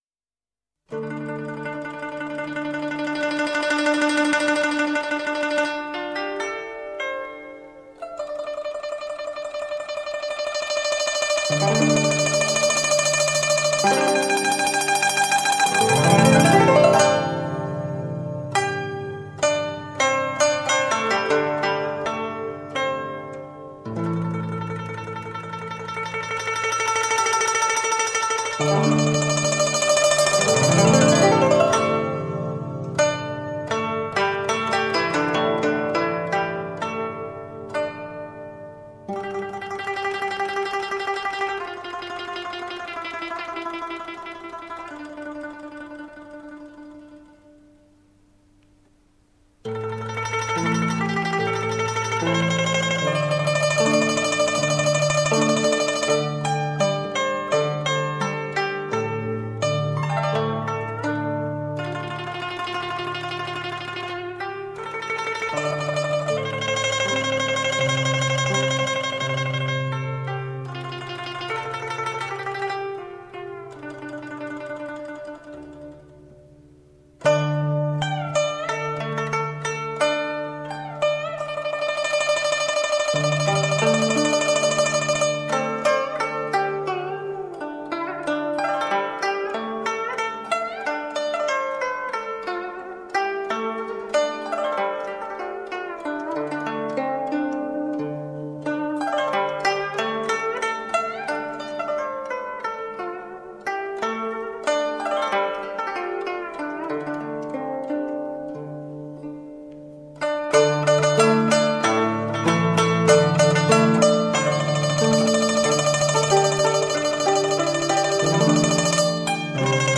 筝的音色，清丽如水，飘然若云，急缓有致的乐音给人清透心扉的淡雅之美。
融入合成器的空灵音质，更显悠渺韵致。